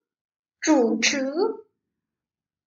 zhǔchí
trủ trứ    chủ trì